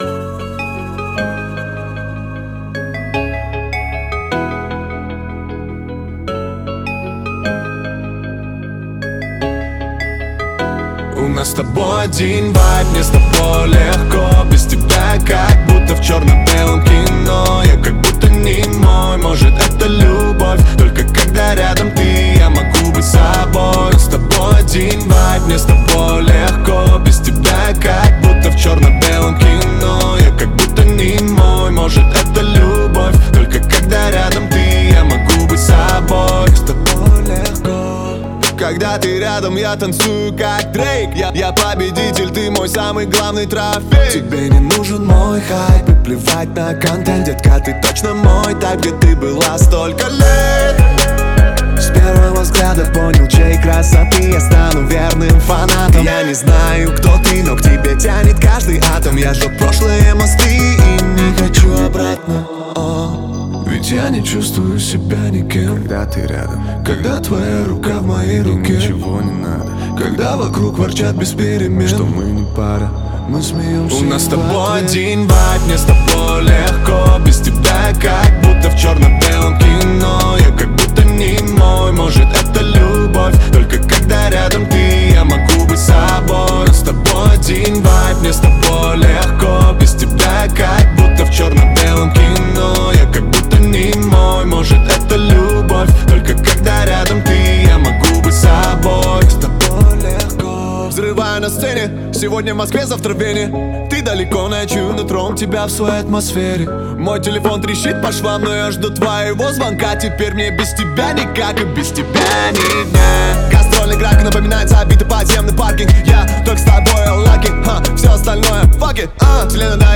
Жанр: Jazz & Blues